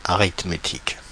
Prononciation
Prononciation Paris France: IPA: [a.ʁit.me.tik] Le mot recherché trouvé avec ces langues de source: français Traduction 1.